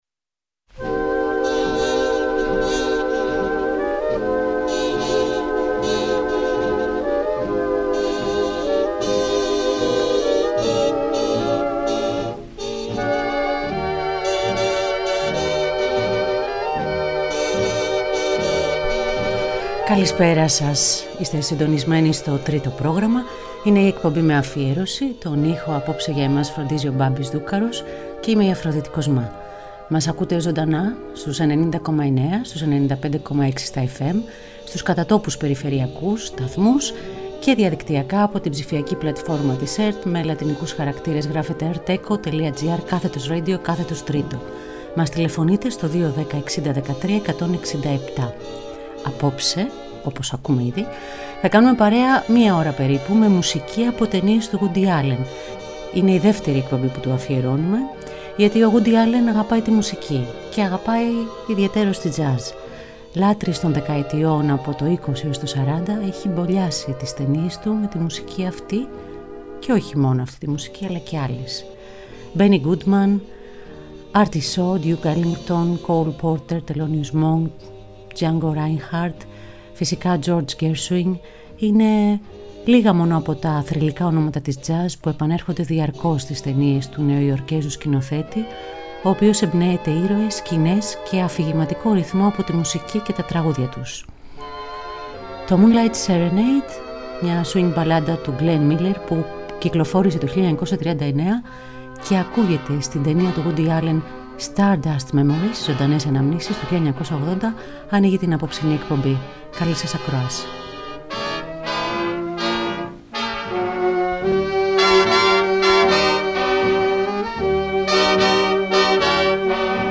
Ο Γούντυ Άλλεν αγαπάει τη μουσική, αγαπάει την τζαζ.